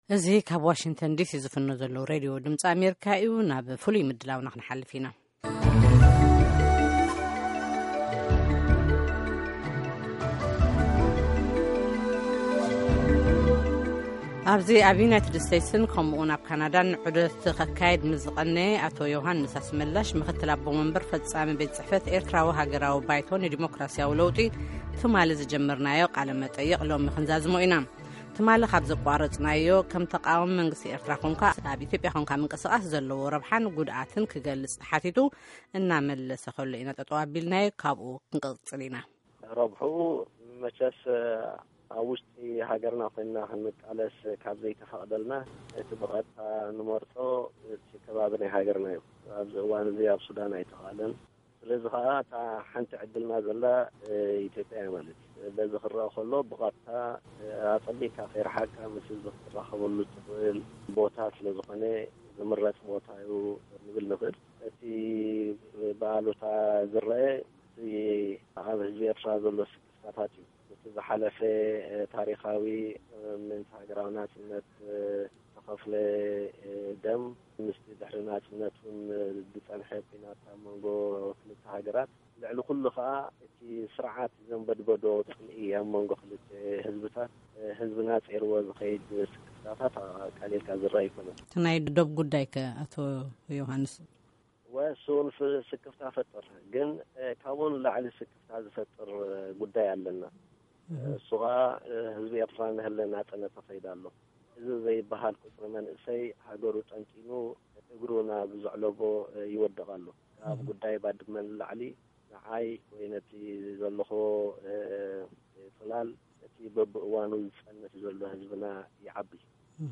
ካልኣይ ክፋል ቃለ-ምልልስ